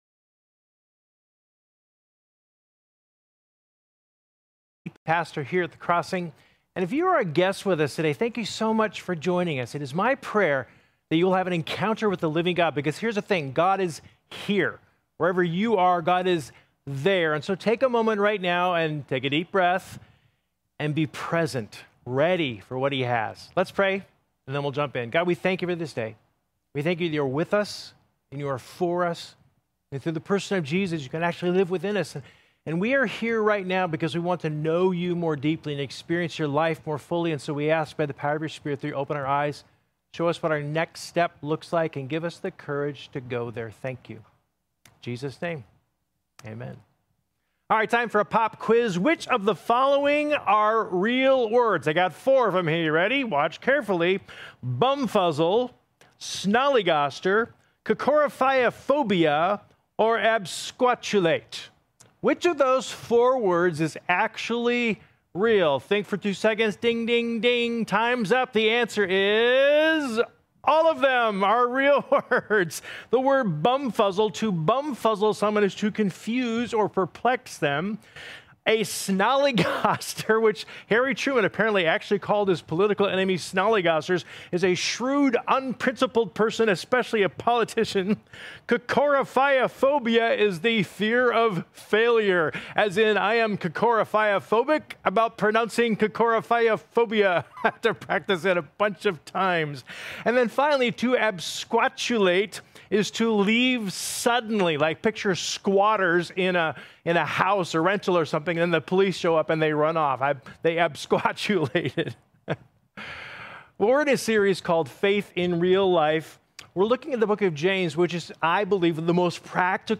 Discussion Questions 1. The sermon said that our words have the power to define us and influence who we are, that what we say is who we become.